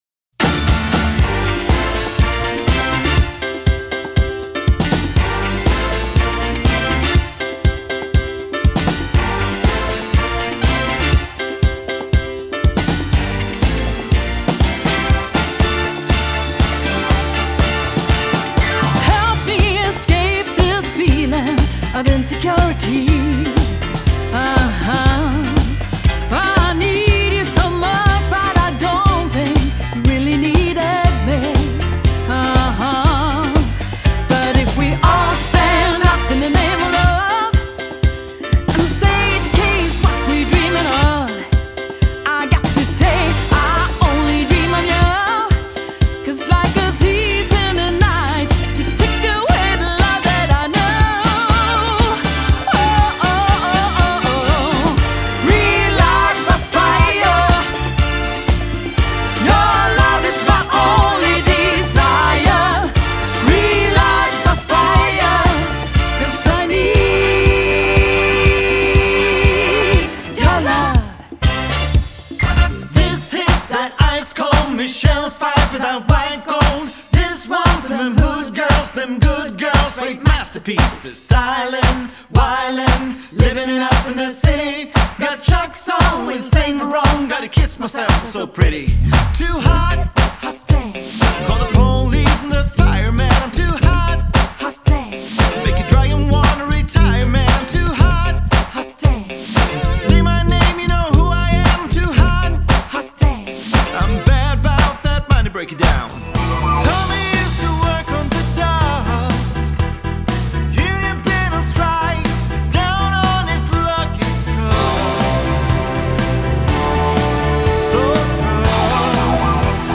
tre sångare som turas om att leada och att köra varann
• Gitarrer
• Bas
• Keyboard
• Trummor
• Sångare